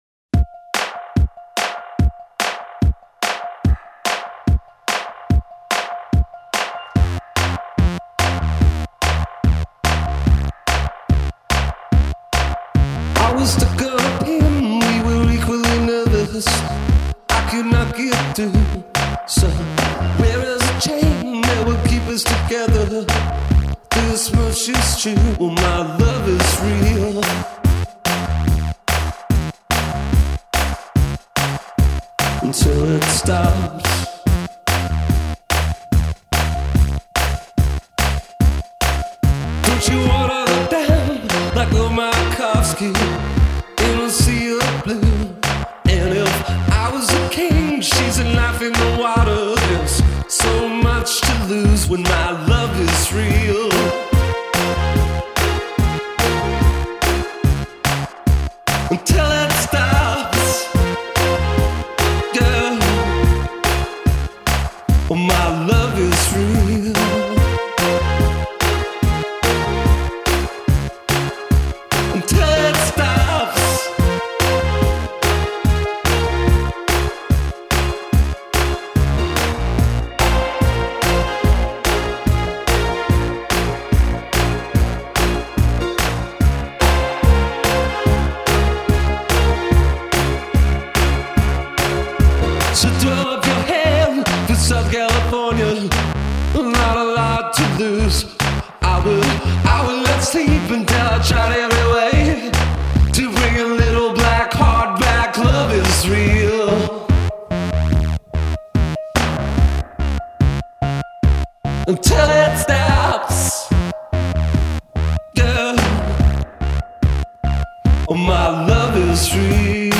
Groovy stuff.